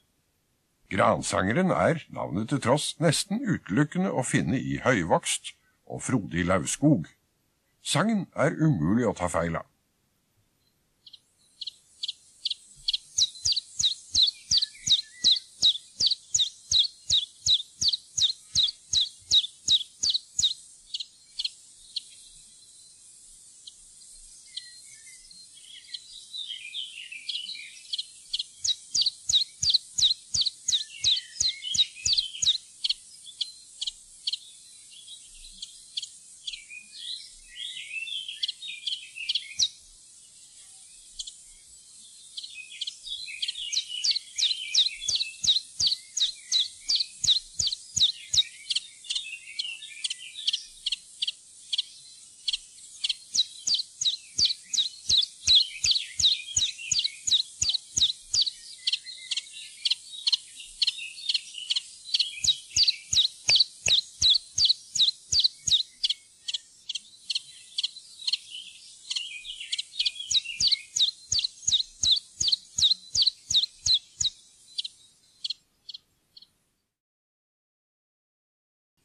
Gransanger
gransanger.mp3